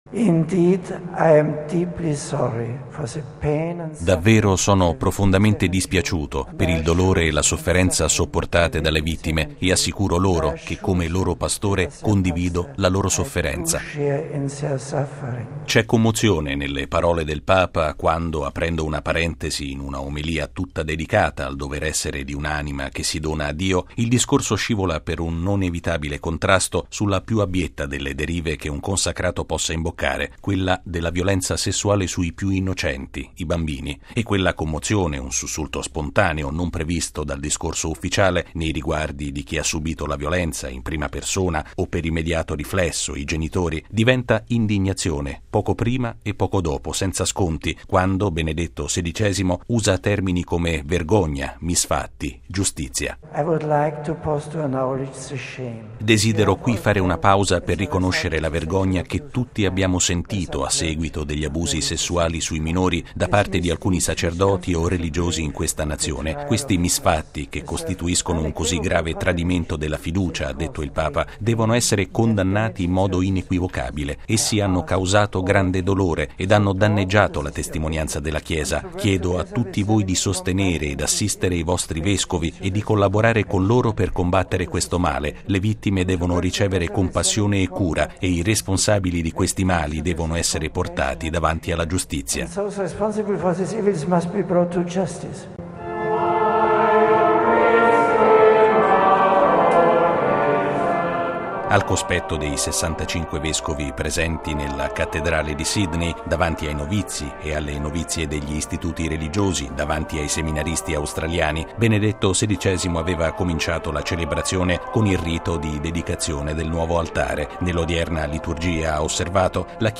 Il sabato di Sydney è cominciato così per Benedetto XVI: con una Messa concelebrata in Cattedrale assieme al clero australiano - ma soprattutto con il futuro di questa Chiesa, i seminaristi, i novizi e le novizie - e con un nuovo atto di solidarietà del Papa verso le vittime degli abusi sessuali commessi da sacerdoti nel Paese.
(canto)